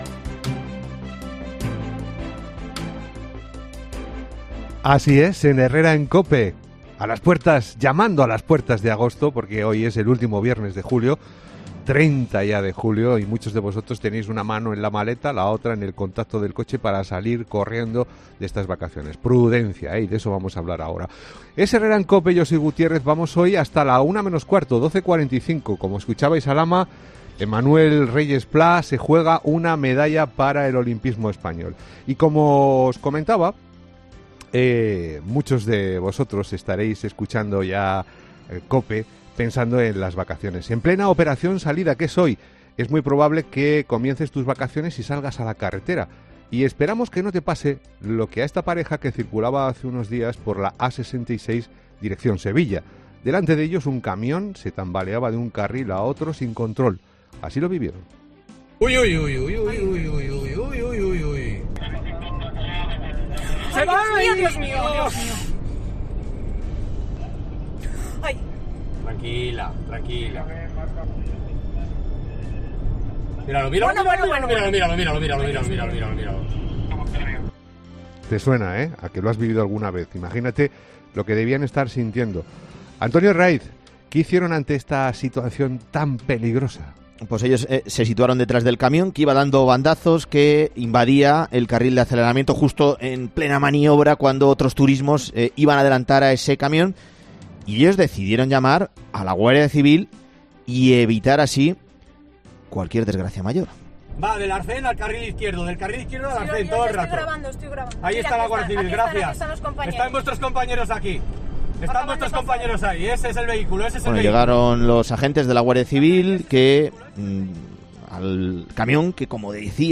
Presentado por Carlos Herrera, el comunicador...